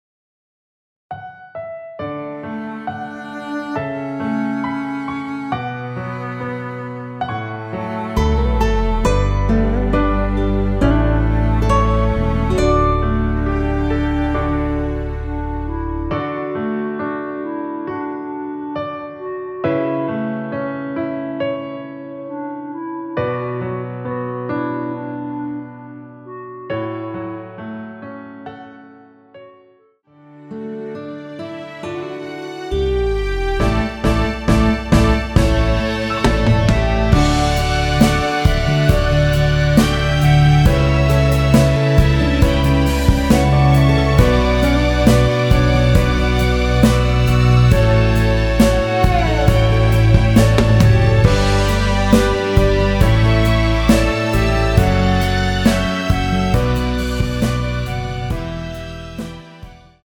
원키에서(-7)내린 멜로디 포함된 MR입니다.
앞부분30초, 뒷부분30초씩 편집해서 올려 드리고 있습니다.
중간에 음이 끈어지고 다시 나오는 이유는